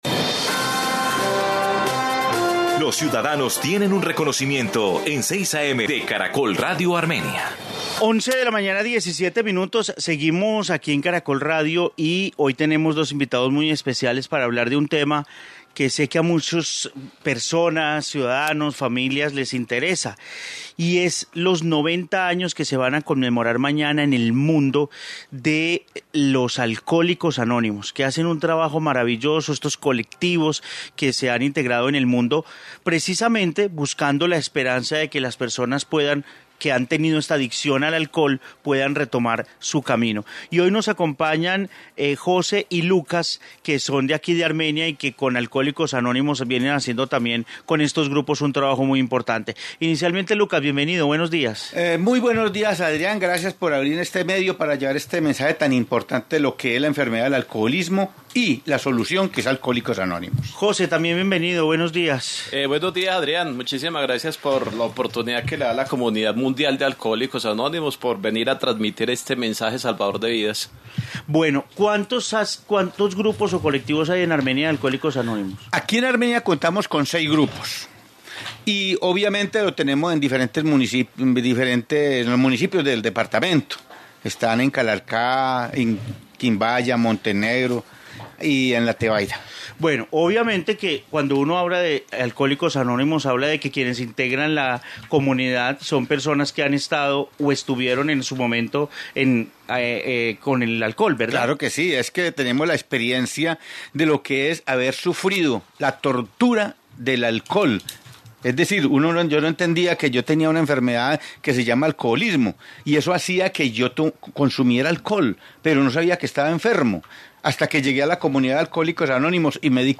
Integrantes de estos grupos relataron su experiencia con el alcohol que también lo iniciaron desde menores de edad
Informe Alcohólicos Anónimos